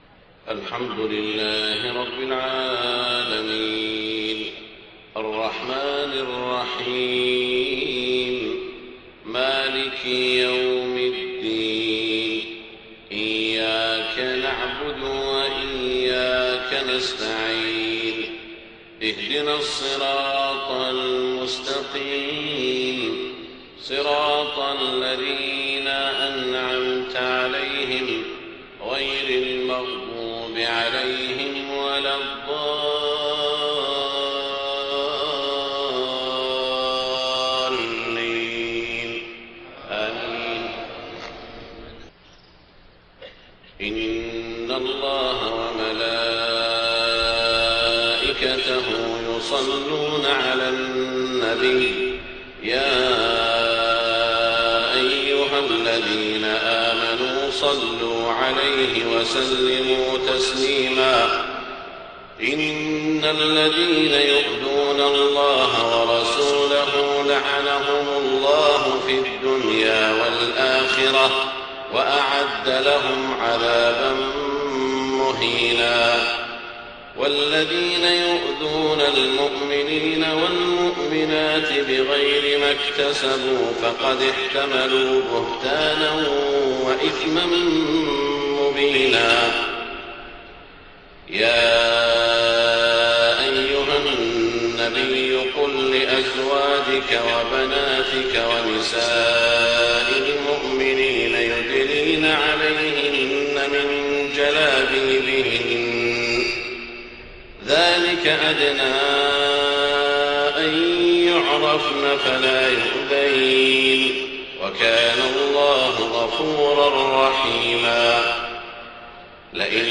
صلاة الفجر 24 ذو الحجة 1429هـ من سورة الأحزاب > 1429 🕋 > الفروض - تلاوات الحرمين